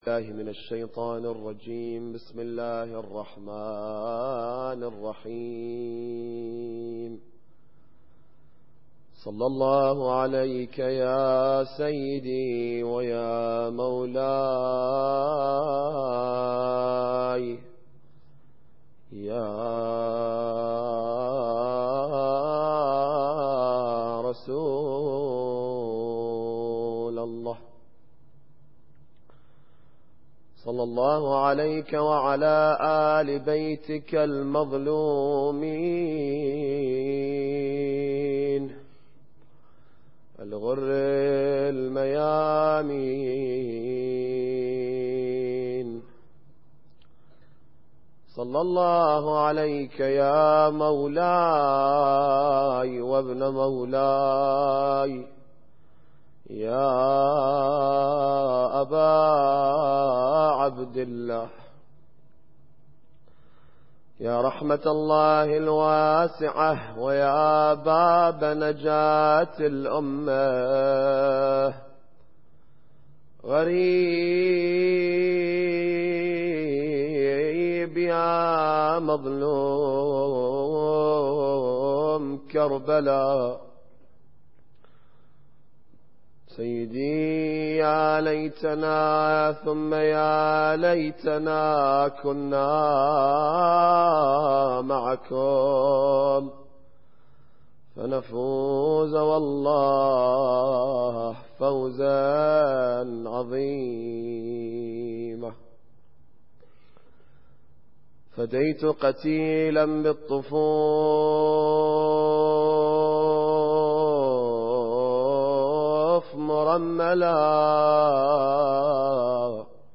Muharram Lecture 10